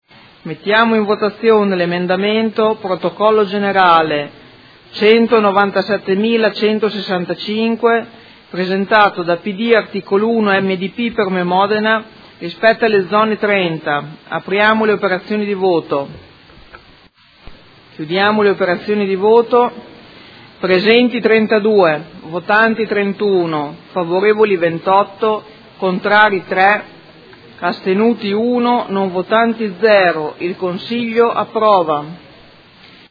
Seduta del 20/12/2018. Mette ai voti emendamento Prot. Gen. 197165